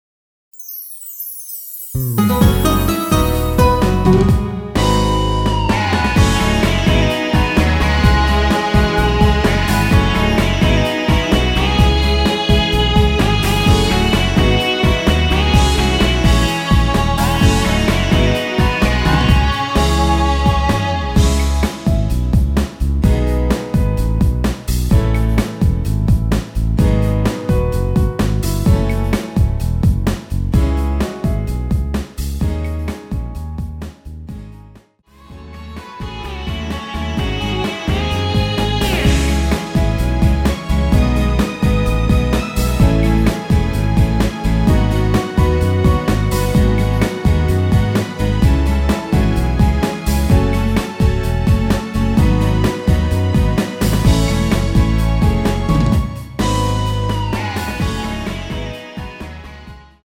Dbm
◈ 곡명 옆 (-1)은 반음 내림, (+1)은 반음 올림 입니다.
앞부분30초, 뒷부분30초씩 편집해서 올려 드리고 있습니다.
중간에 음이 끈어지고 다시 나오는 이유는